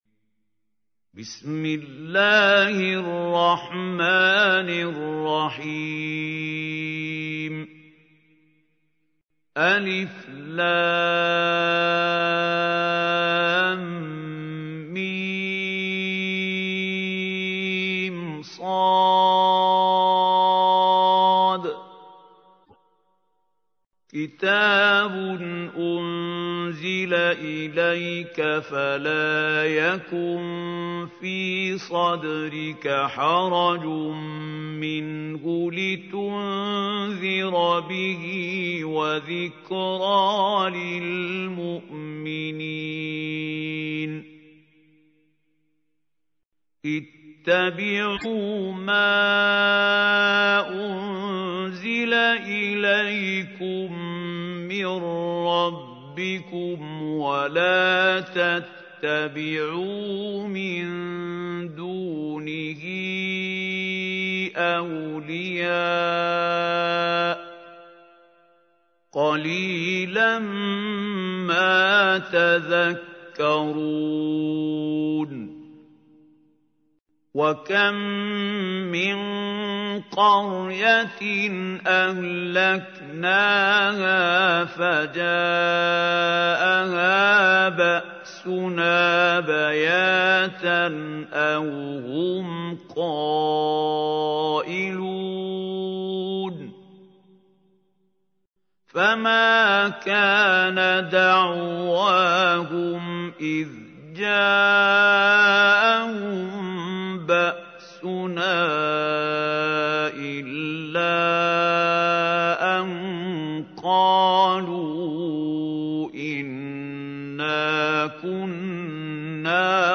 تحميل : 7. سورة الأعراف / القارئ محمود خليل الحصري / القرآن الكريم / موقع يا حسين